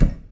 ignite.ogg